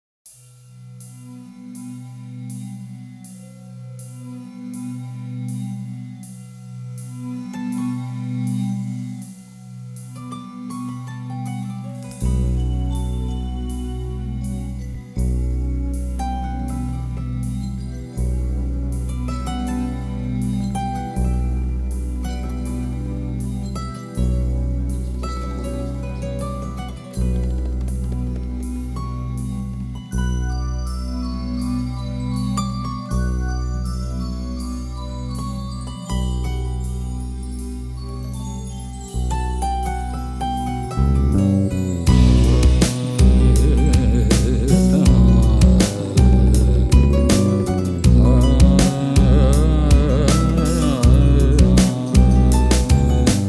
Genre: World Fusion.
flutes, keyboards
kora, cello
vocals
electric fretless bass
congas, bongos, and percussion/special effects